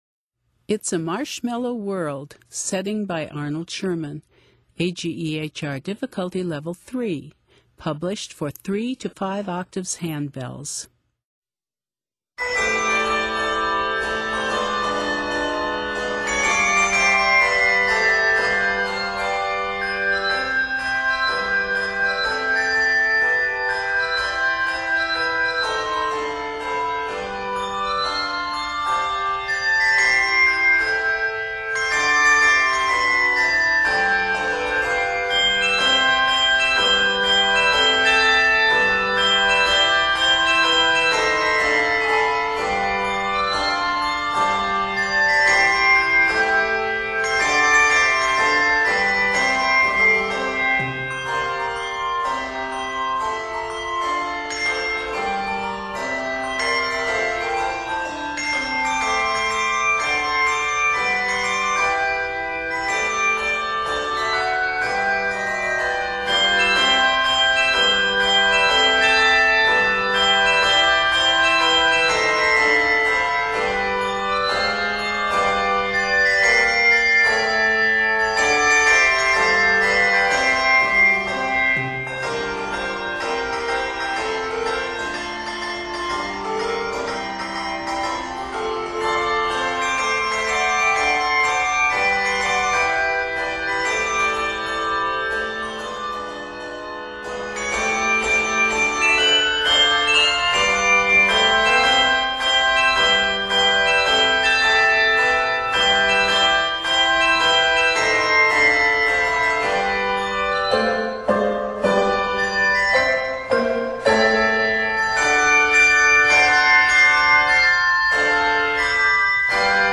delightful toe-tapping setting
Octaves: 3-5